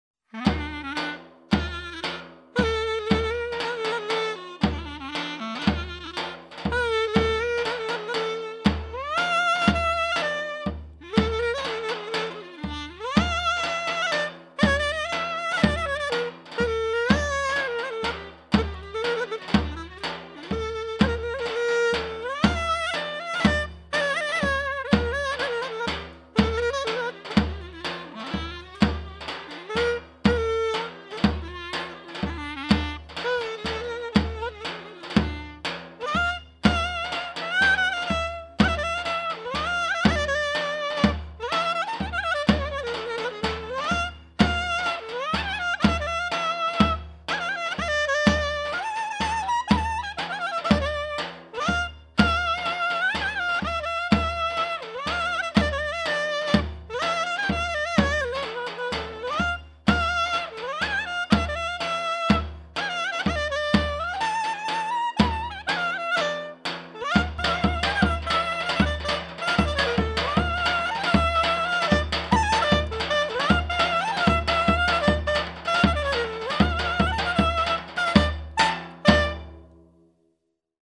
Sözlü, Sözsüz Yöresel Müzikler